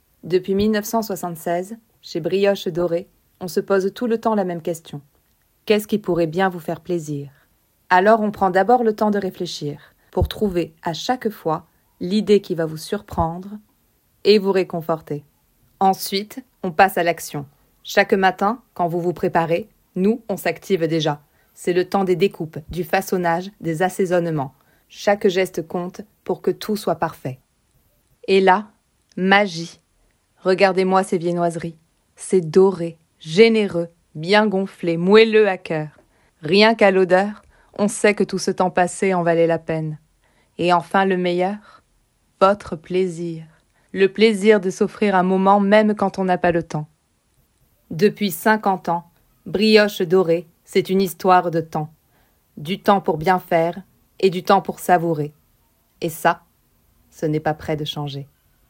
Voix off
20 - 45 ans - Mezzo-soprano